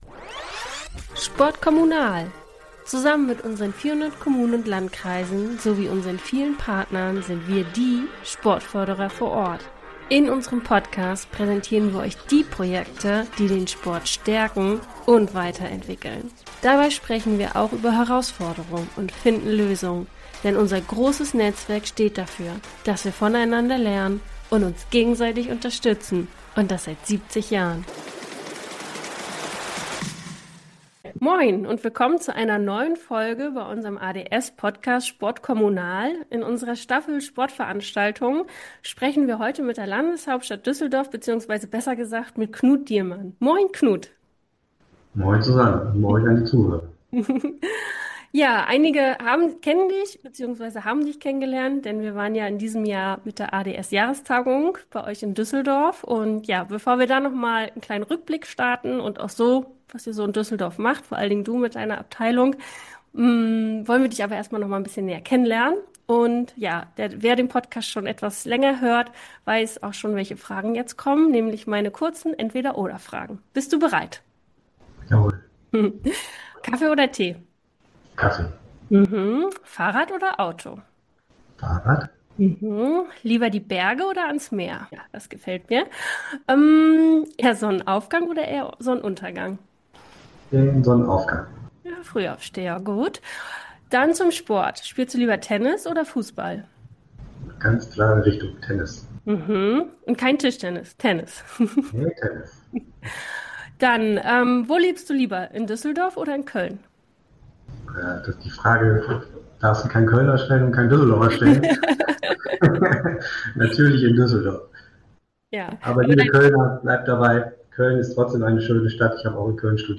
Ein Gespräch über Visionen, Verantwortlichkeiten – und darüber, was wirklich bleibt und wie wir gemeinsam in den Sportämtern von einander lernen können.